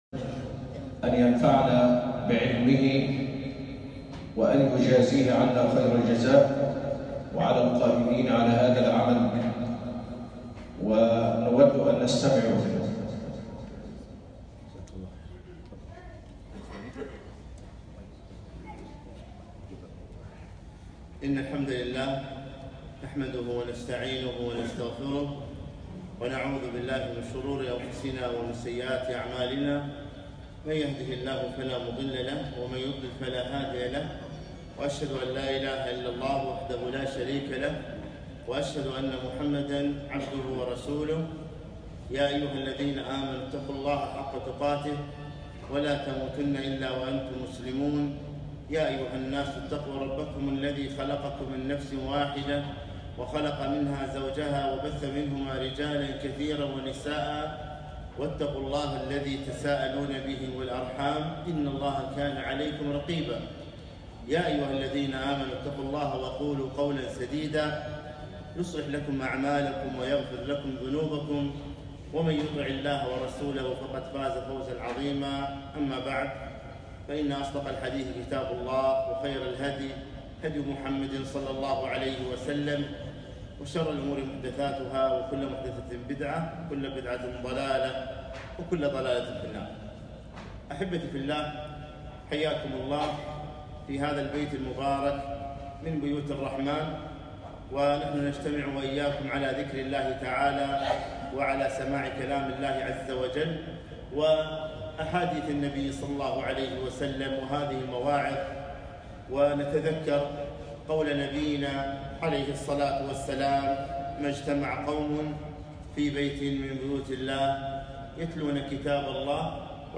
محاضرة - ولا تنسوا الفضل بينكم